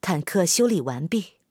SU-122A修理完成提醒语音.OGG